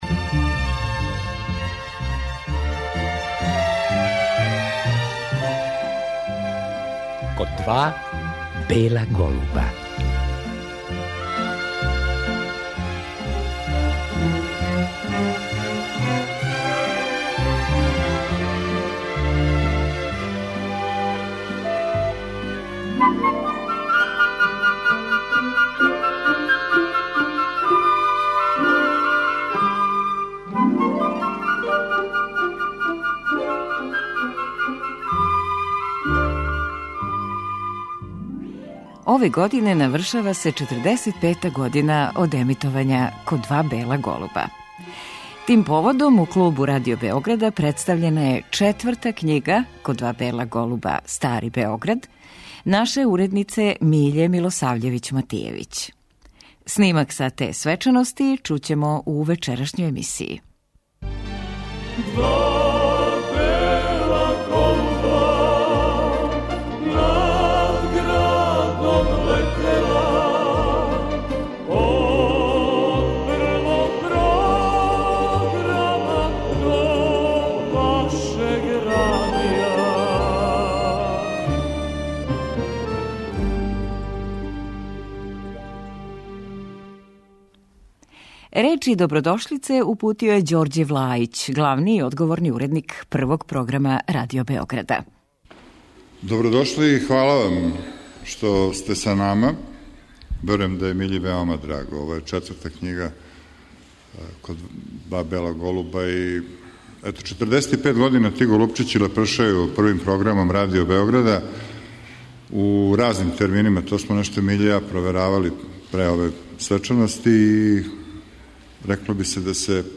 Снимак са ове свечаности емитујемо у вечерашњем термину Код два бела голуба.